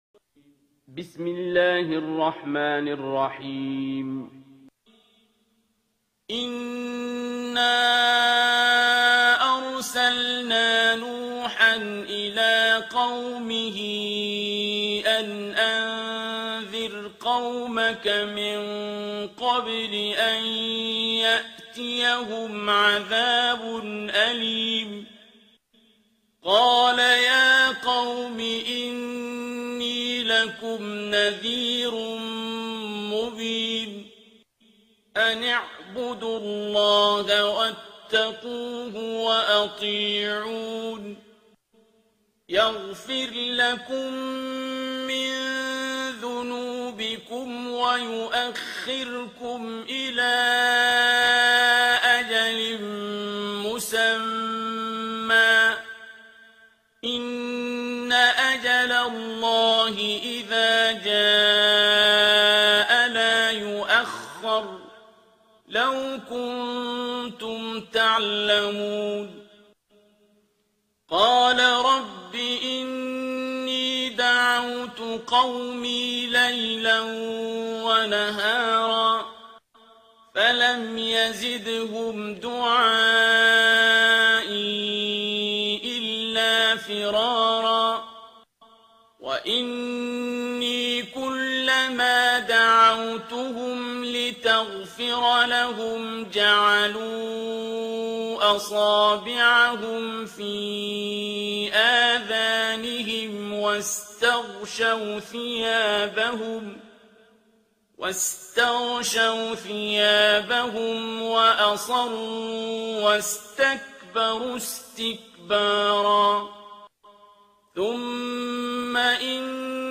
ترتیل سوره نوح با صدای عبدالباسط عبدالصمد